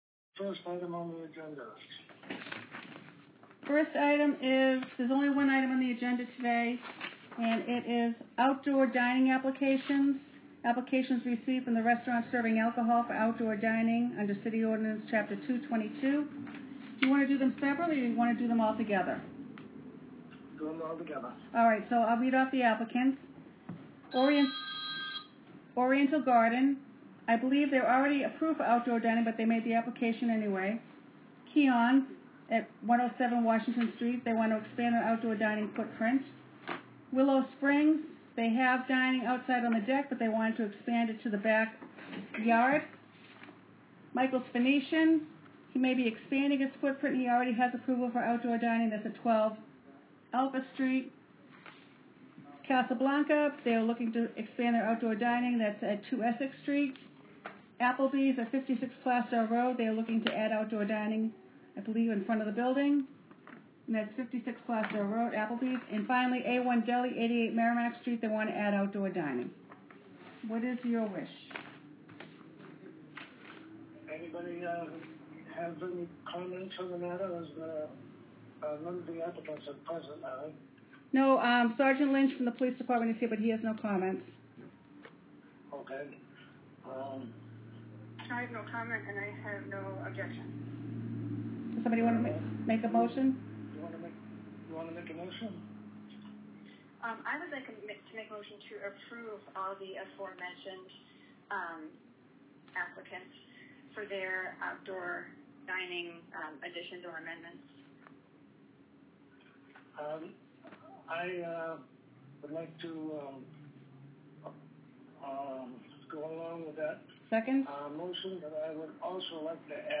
June 15 2020 -special -- remote -